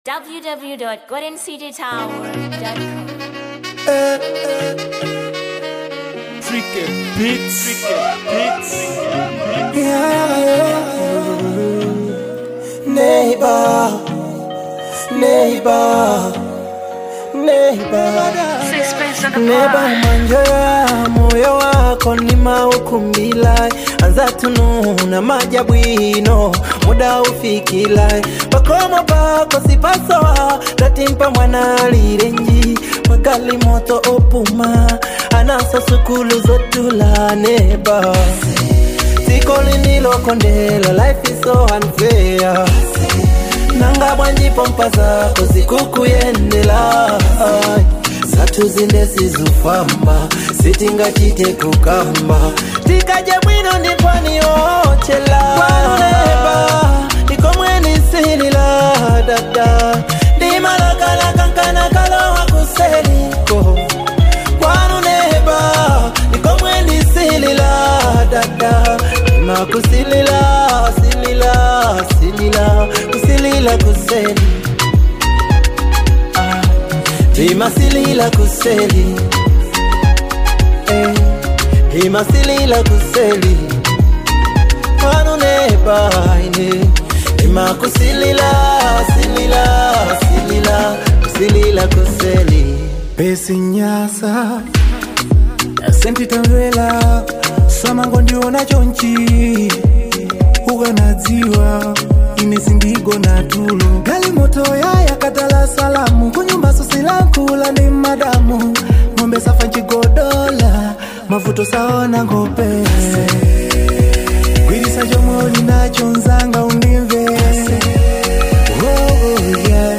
2. Afro Pop